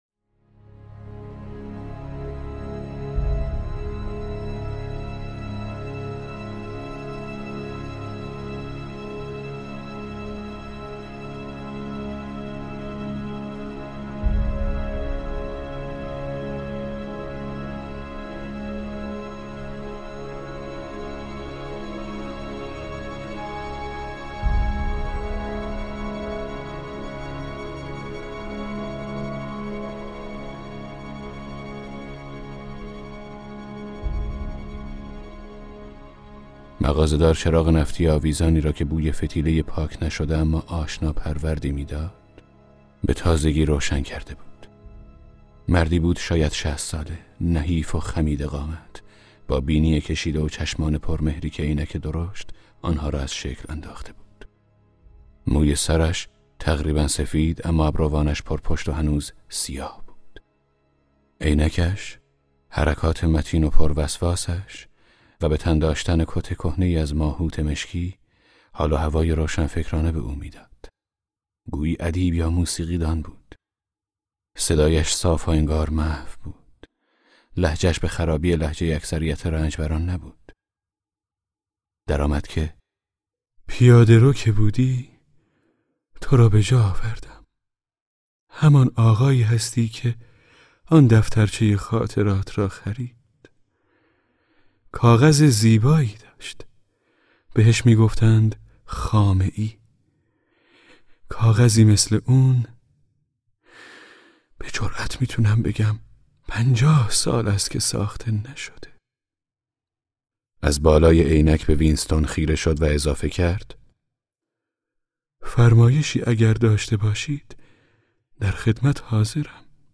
کتاب صوتی 1984 اثر جورج اورول - قسمت 11